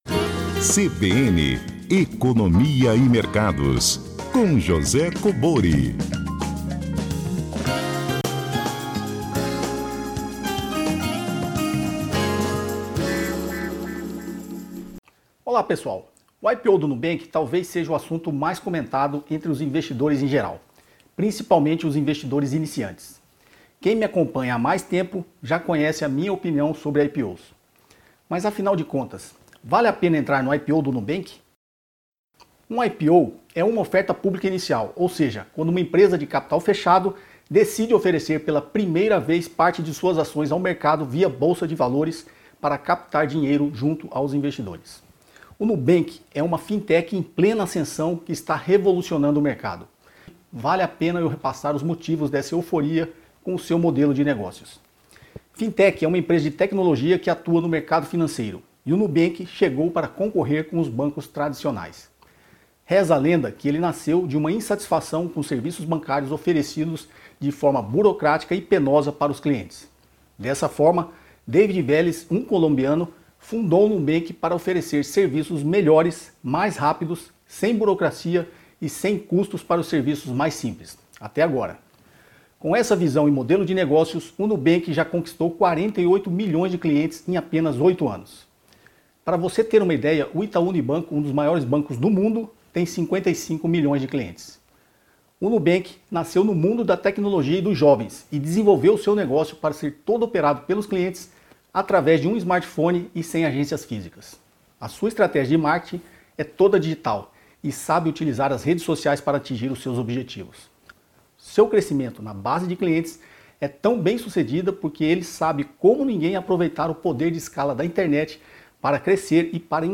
Manaus Amazonas Comentarista Economia e Mercado IPO Nubank